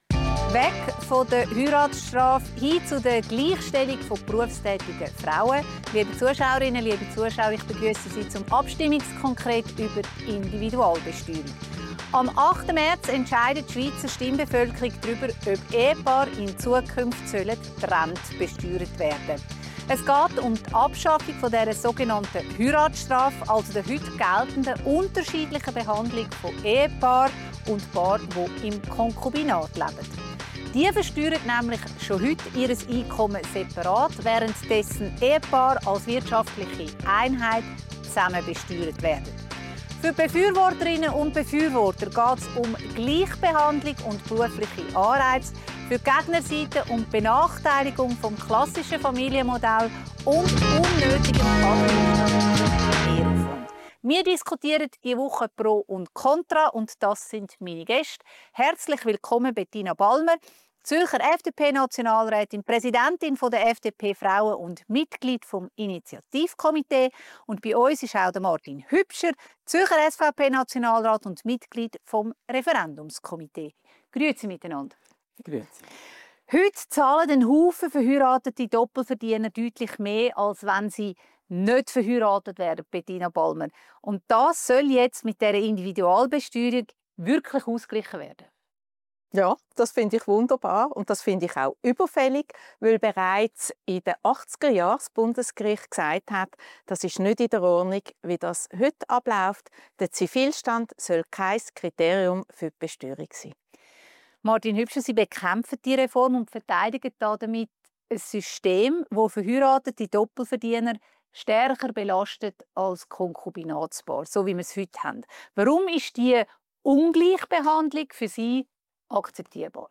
diskutiert im Abstimmungskonkret über die Individualbesteuerung vom 8. März mit den beiden Zürcher Nationalräten Bettina Balmer, FDP und Martin Hübscher, SVP.